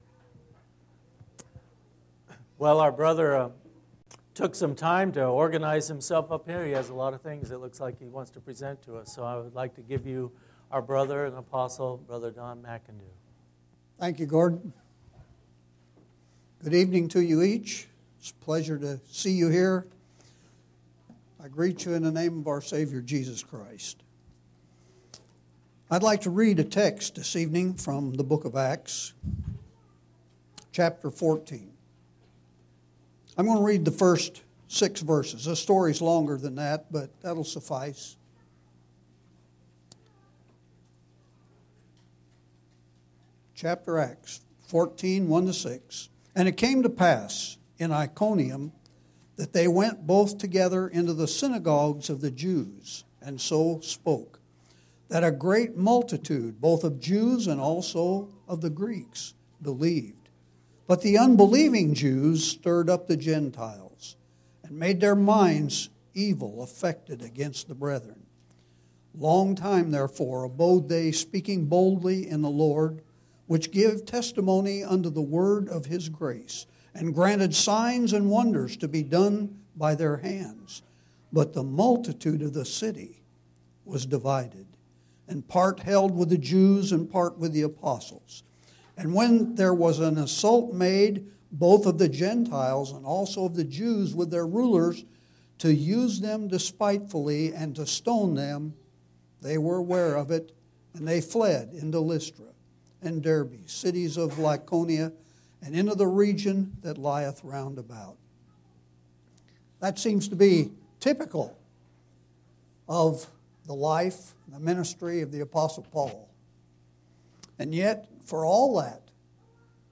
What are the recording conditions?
5/17/2009 Location: Phoenix Local Event